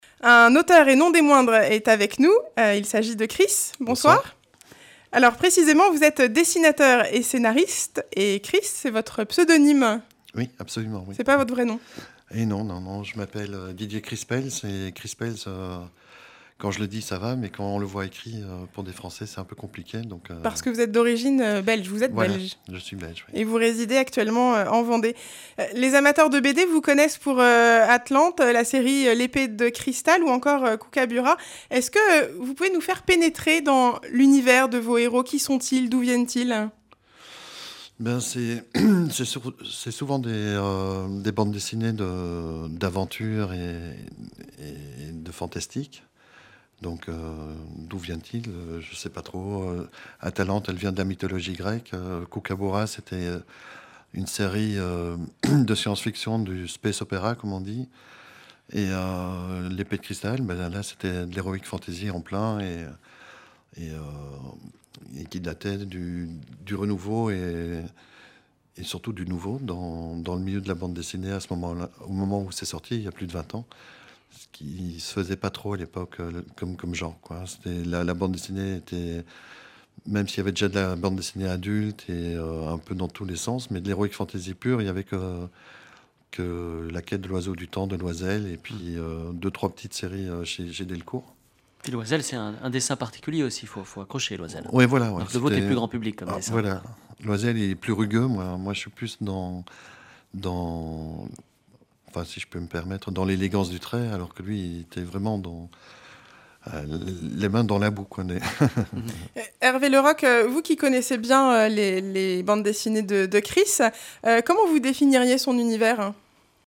Emissions de la radio RCF Vendée
Catégorie Témoignage